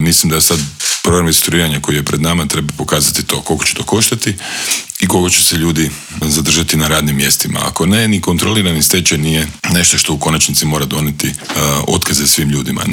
Bivši ministar gospodarstva u SDP-ovoj Vladi Ivan Vrdoljak o novom planu kaže: